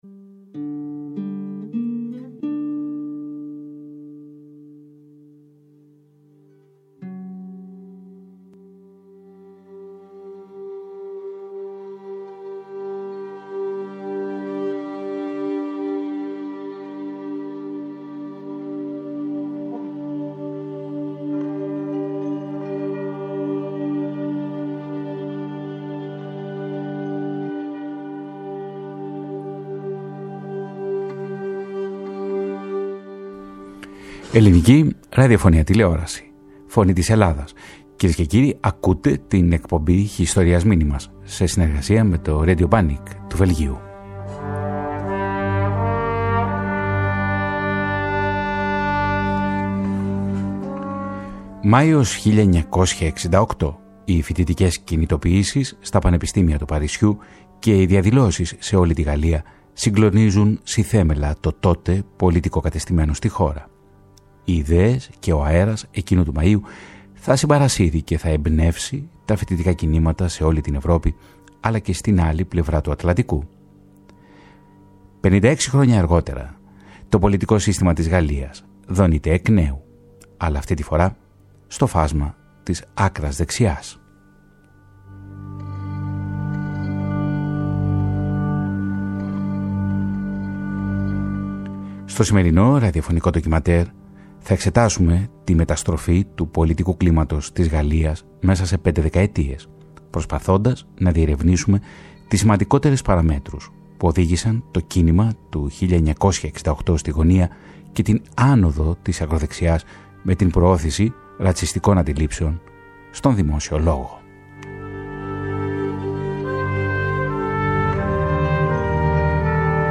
ένα ραδιοφωνικό ντοκιμαντέρ